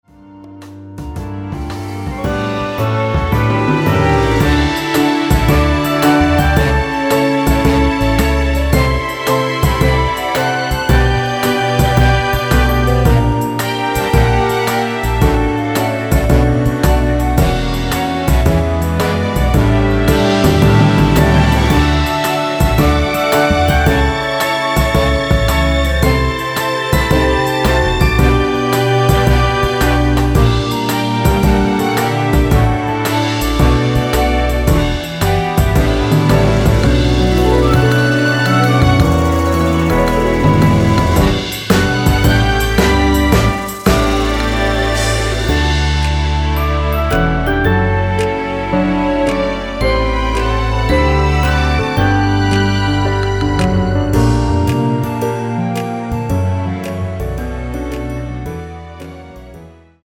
다음 간주가 길어서 4마디로 짧게 편곡 하였습니다.(미리듣기및 본문가사 확인)
원키 (1절+후렴)으로 진행되는 멜로디 포함된 MR입니다.
앞부분30초, 뒷부분30초씩 편집해서 올려 드리고 있습니다.
중간에 음이 끈어지고 다시 나오는 이유는